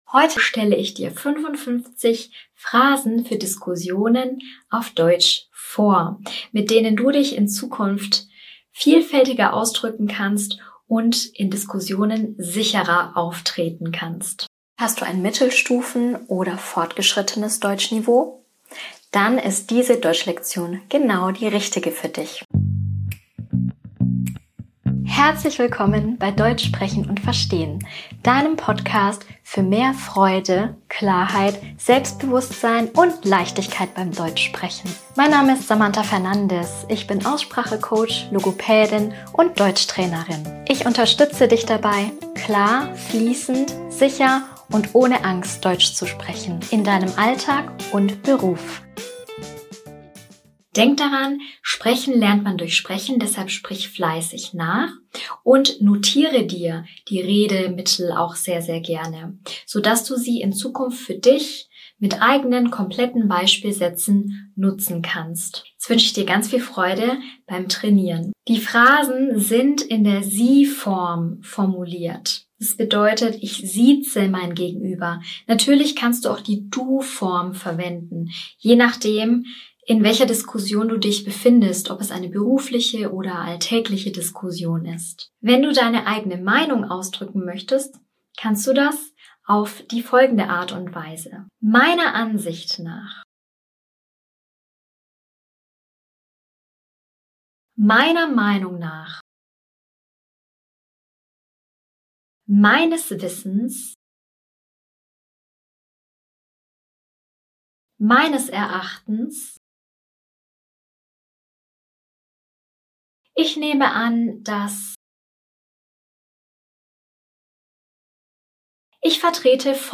In dieser Folge lernst du 55 wichtige deutsche Phrasen, mit denen du deine Meinung klar ausdrücken, reagieren und Gespräche aktiv führen kannst (B2, C1). Hör zu sprich in den Pausen laut nach und entwickle ein sicheres Sprachgefühl für fließendes Deutsch.